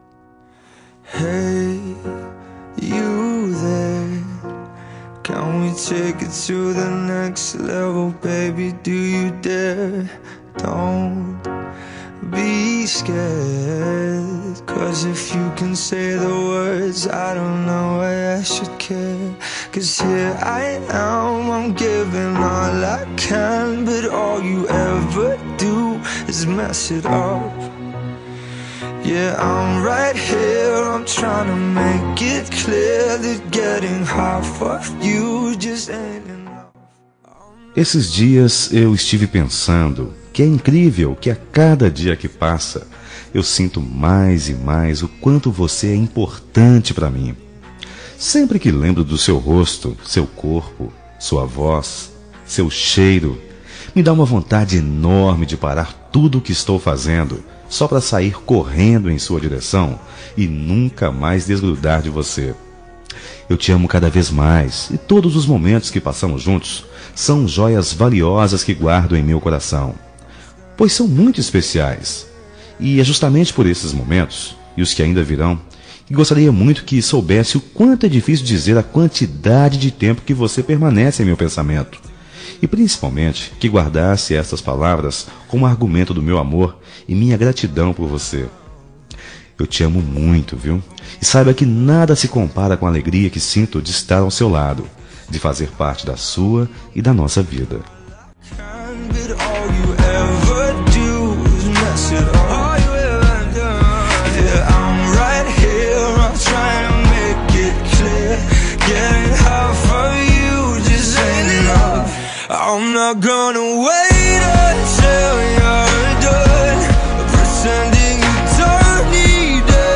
Telemensagem Romântica para Esposa – Voz Masculina – Cód: 9074